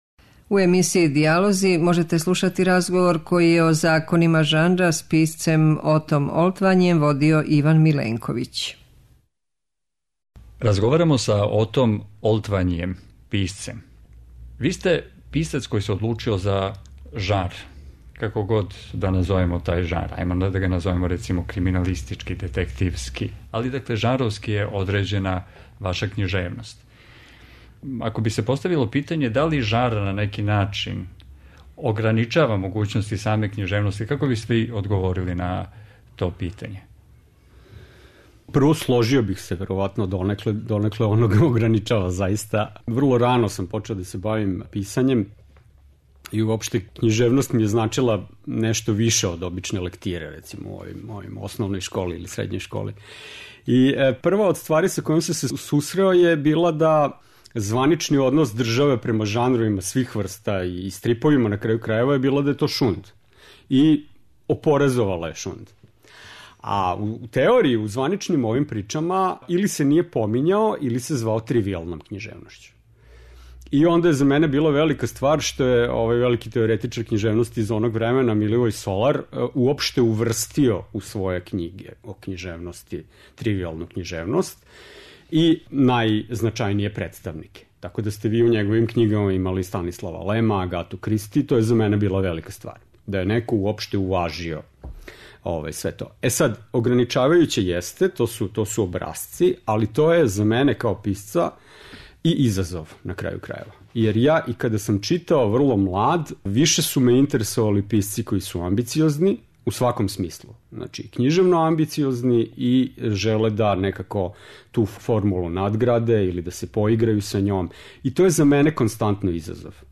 преузми : 19.43 MB Радијска предавања, Дијалози Autor: Трећи програм Из Студија 6 директно преносимо јавна радијска предавања.